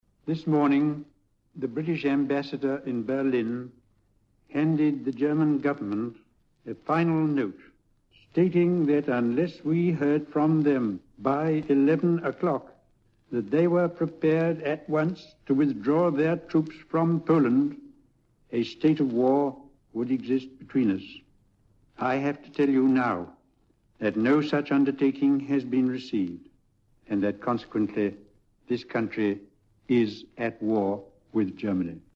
The moment Britain's Prime Minister Neville Chamberlain announced that we were at war with Germany, 75 years ago today, marking the start of World War 2.